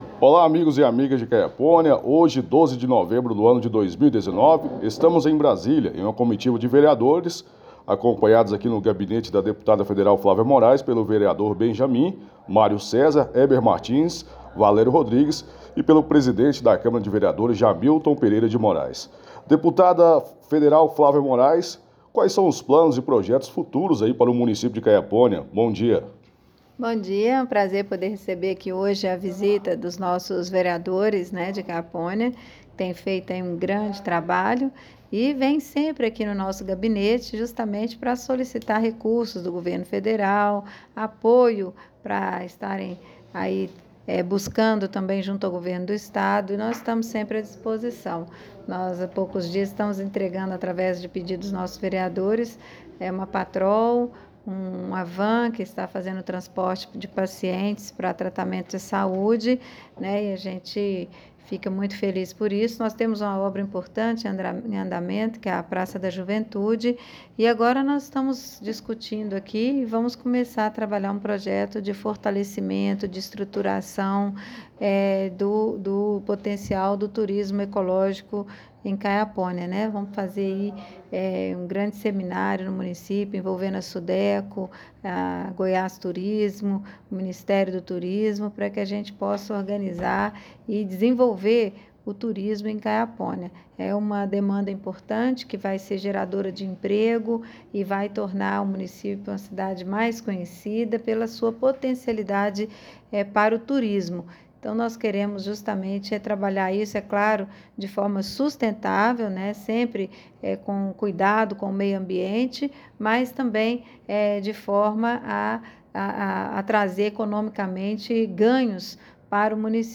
Entrevista com a deputada federal Flávia Morais Brasília-DF